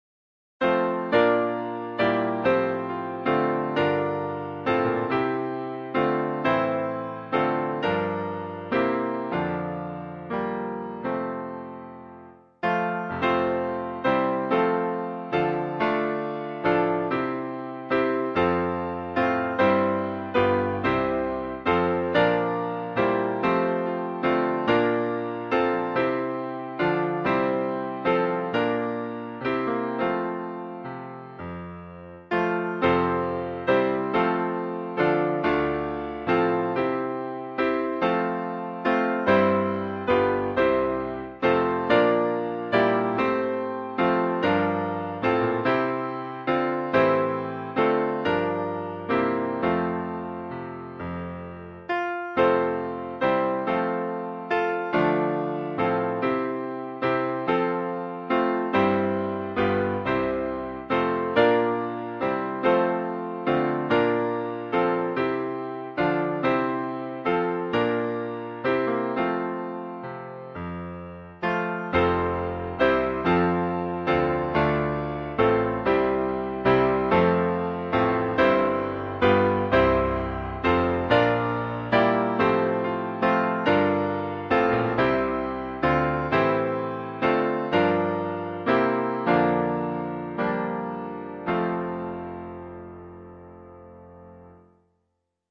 导读：本颂赞诗歌歌谱采用2017年修订版，录音示范暂用旧版，将逐渐更新。 独唱示范演唱者
原唱